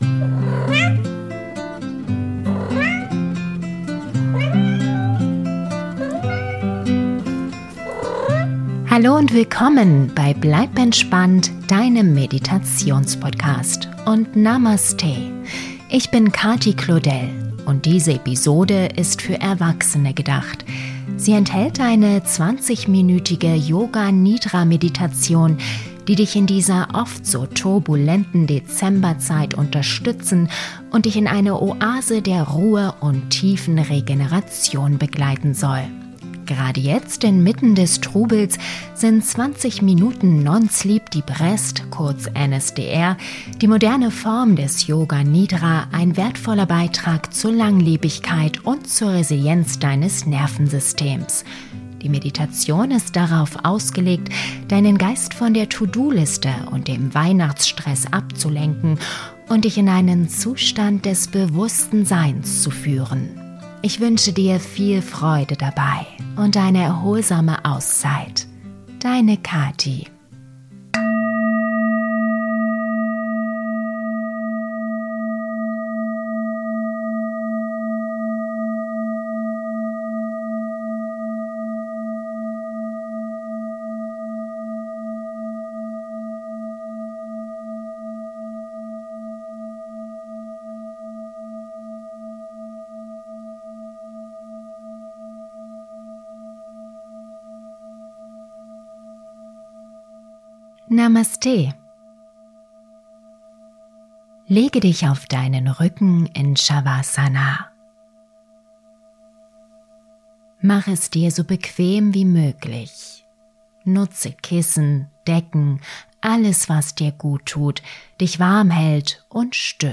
Ideale Schlafvorbereitung: Die perfekte Brücke zwischen einem stressigen Tag und dem Tiefschlaf.
Möchtest du Yoga Nidra ohne Unterbrechungen genießen und die maximale Wirkung erzielen?